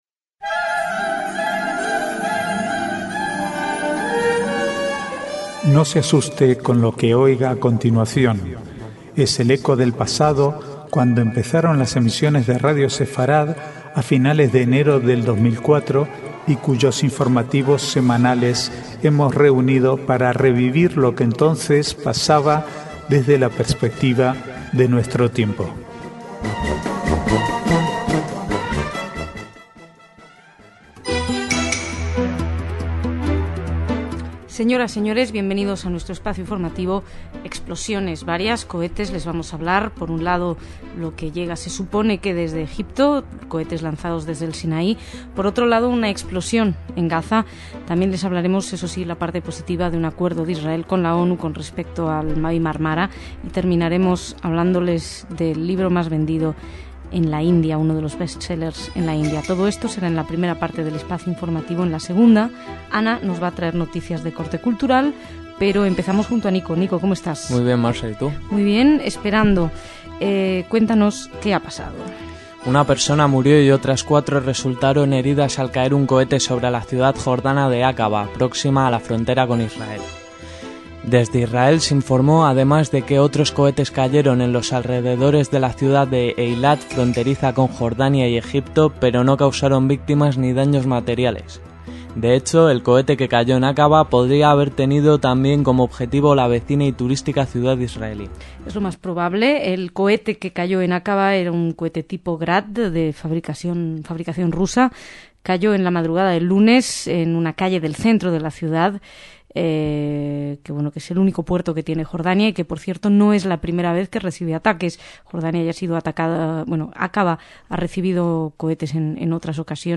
Archivo de noticias del 3 al 6/8/2010